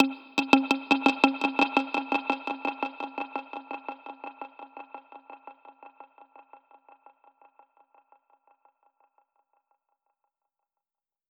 DPFX_PercHit_B_85-05.wav